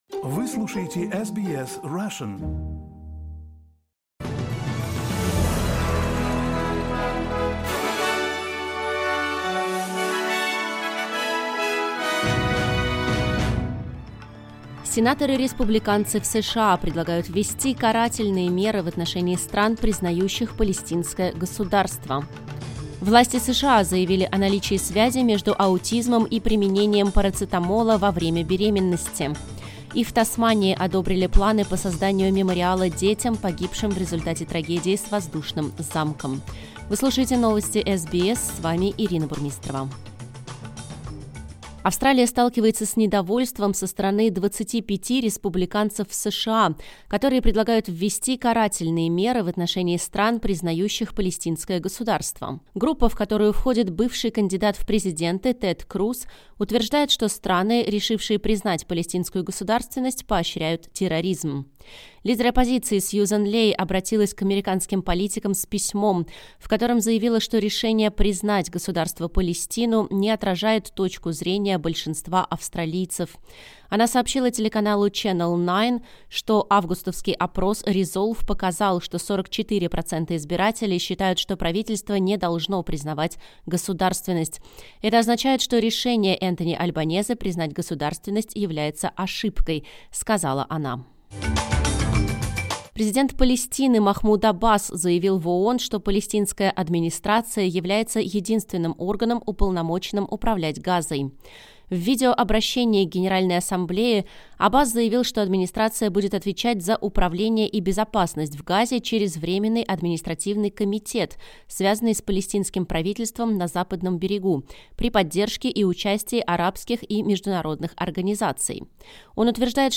Новости SBS на русском языке — 23.09.2025